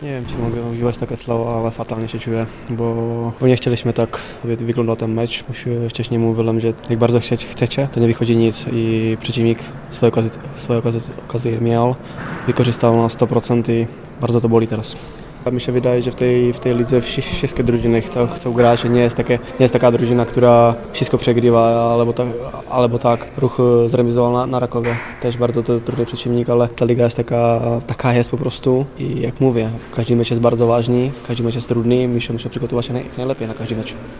Bolesną porażkę skomentował również Petr Schwarz, pomocnik WKS-u.